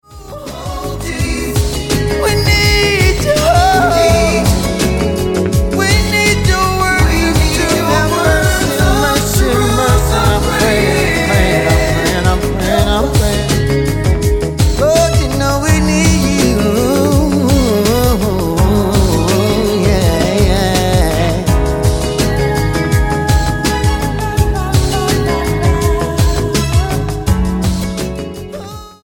STYLE: R&B
old-style production